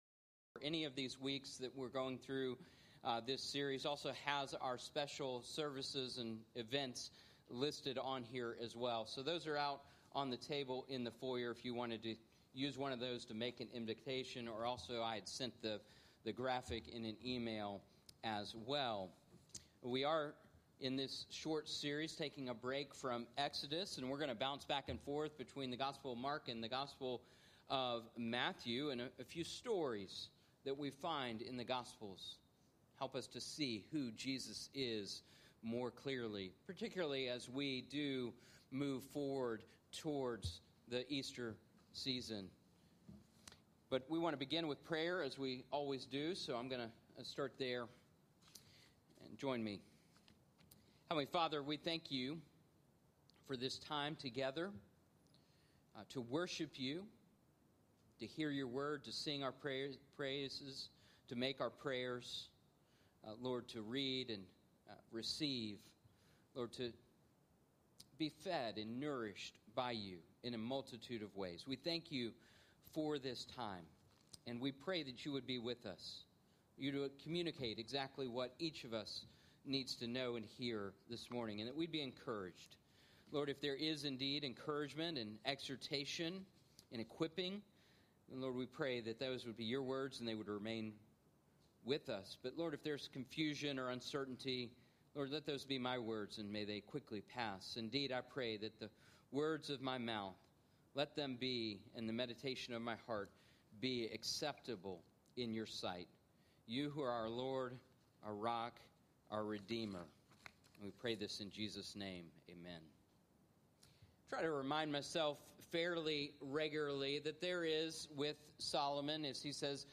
North Hills Church (PCA) Sermons